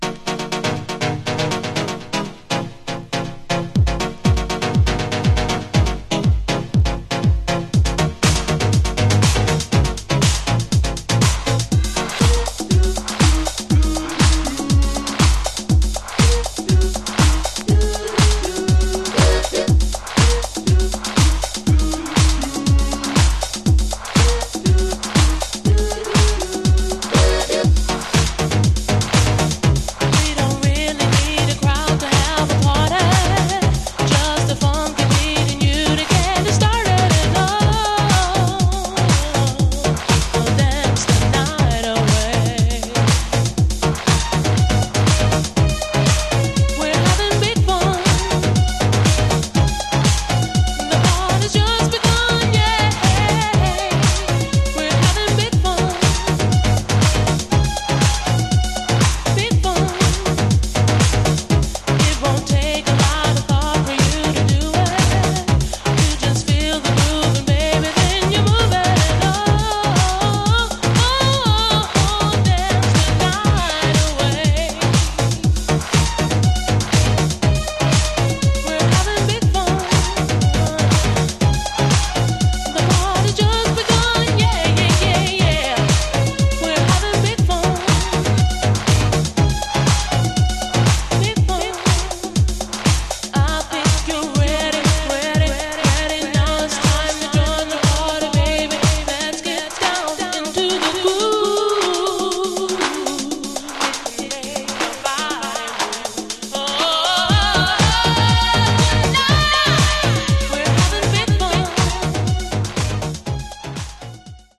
This fantastic techno dancer has two special-for-45 versions